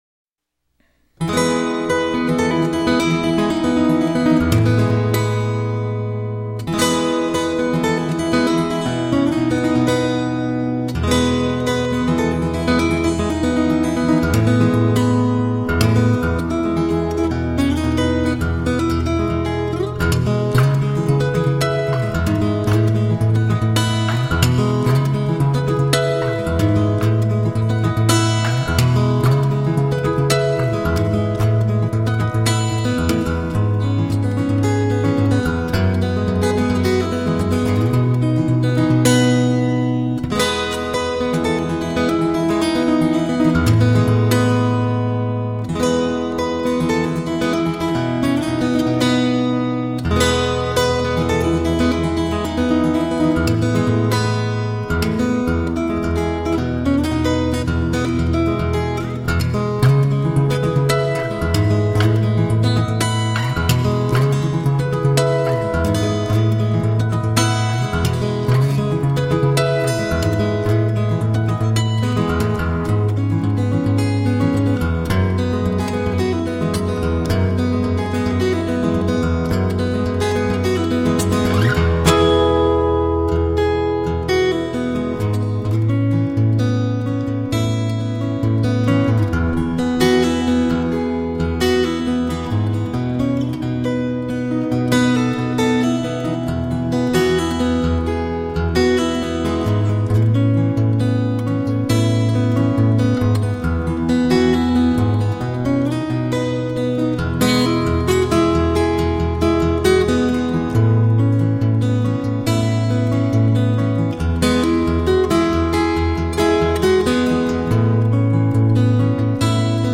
Evocative, melodic and haunting instrumental guitar music.